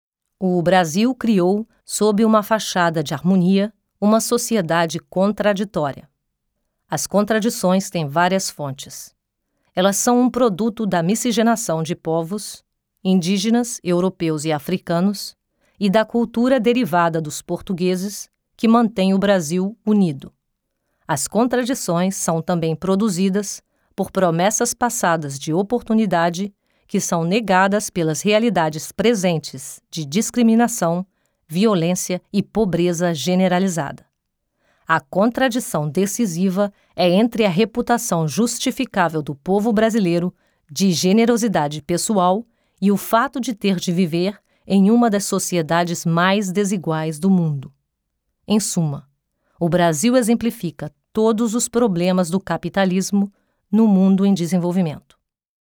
Portugiesich/Brasilianische Sprachaufnahmen von professioneller brasilianischer Schauspielenrin für Image, VO, Werbung und Ansagen.
Sprechprobe: Industrie (Muttersprache):